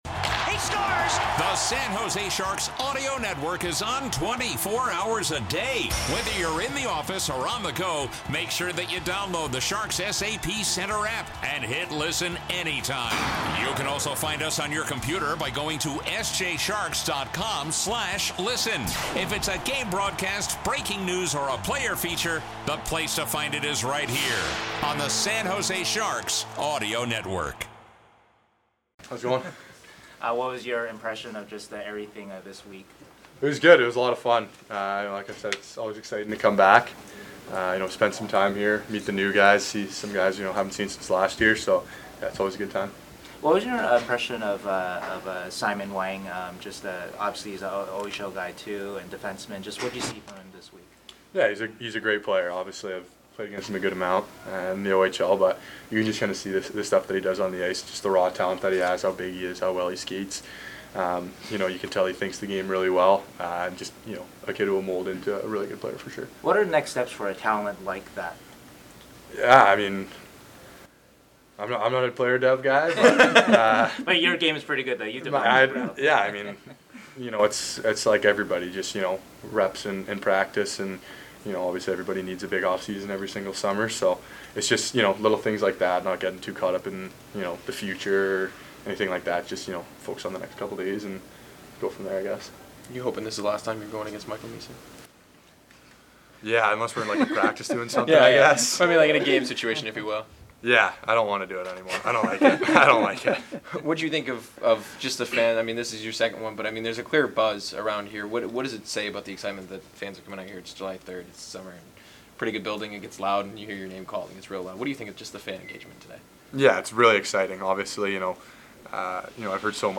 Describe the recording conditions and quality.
spoke with the media after Thursday's Prospect Scrimmage.